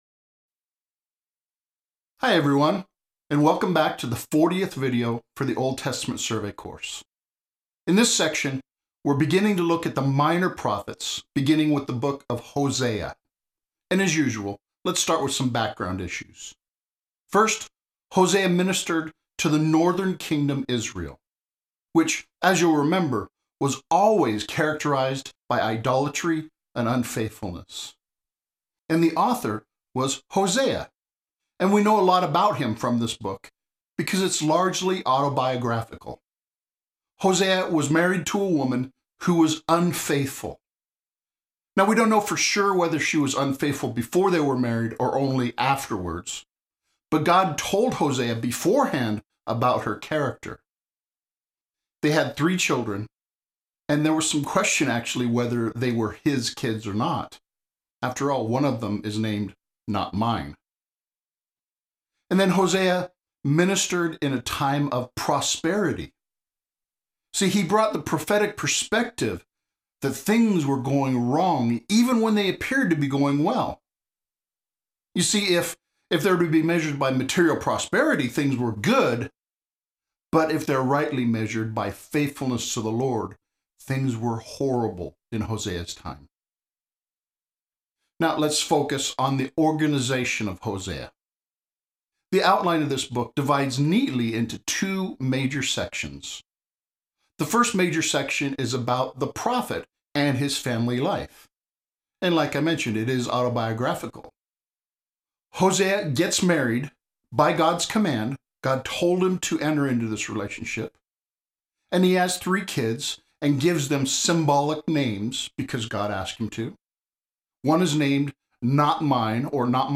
The second section has a survey video lecture covering the entire book.